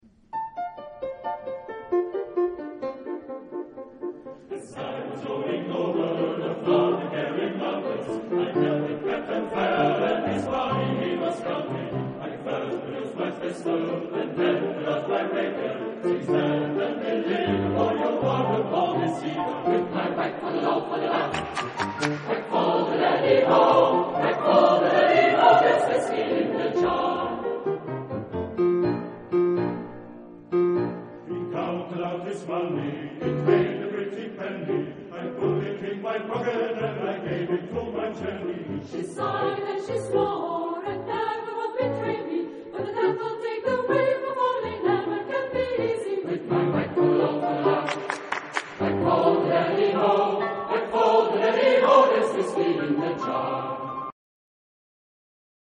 Genre-Style-Form: Popular ; Secular ; Traditional ; Drinking song
Mood of the piece: fast ; joyous ; dancing
Type of Choir: SATB  (4 mixed voices )
Instrumentation: Piano  (1 instrumental part(s))
Tonality: F major